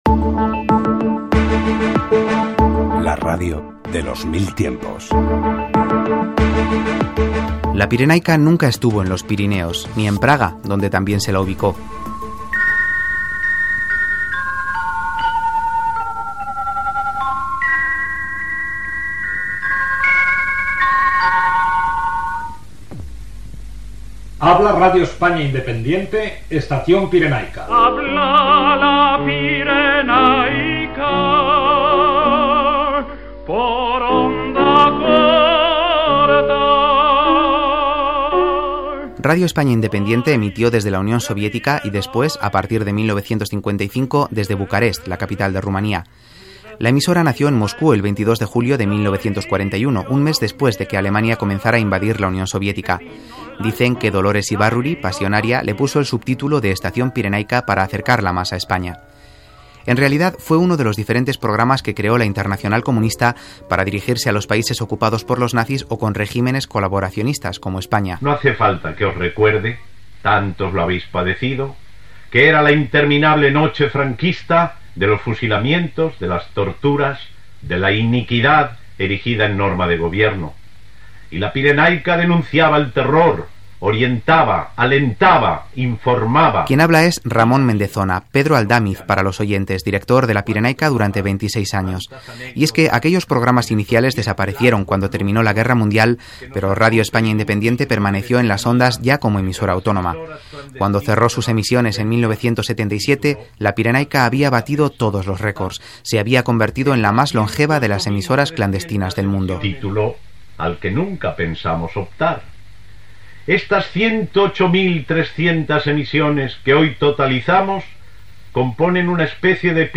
Careta del programa i espai dedicat a Radio España Independiente "Estación Pirenaica"
Divulgació